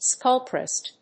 /skˈʌlptrəs(米国英語)/